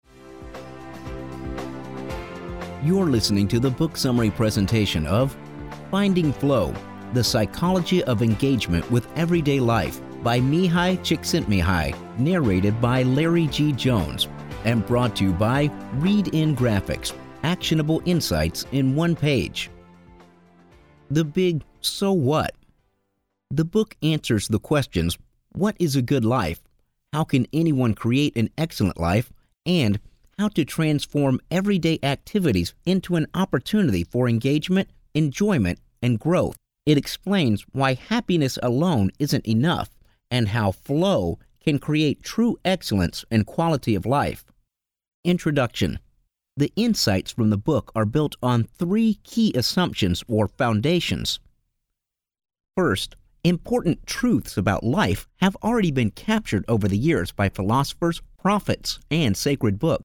Male
Natural Speak
This Is My Natural Voice